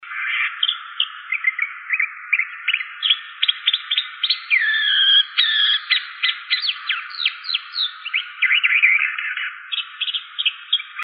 Calhandra-de-três-rabos (Mimus triurus)
Nome em Inglês: White-banded Mockingbird
Fase da vida: Adulto
Localidade ou área protegida: Reserva Ecológica Costanera Sur (RECS)
Condição: Selvagem
Certeza: Gravado Vocal